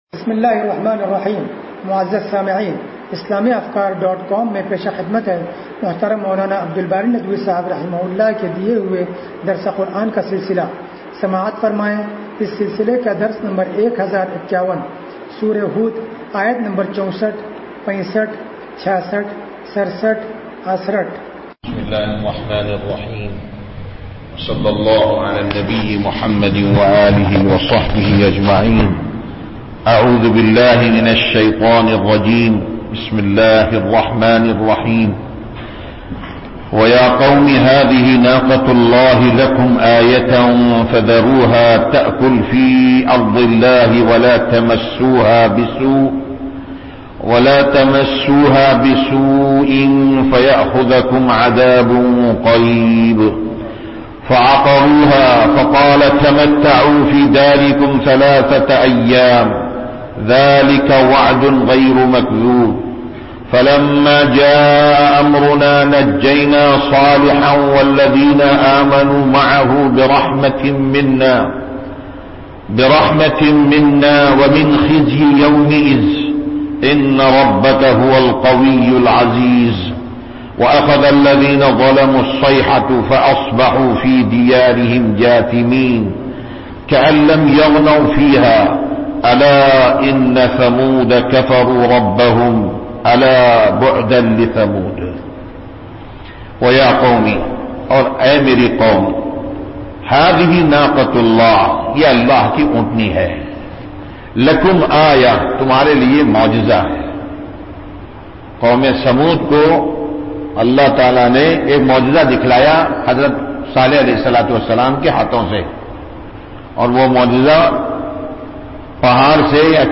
درس قرآن نمبر 1051
درس-قرآن-نمبر-1051.mp3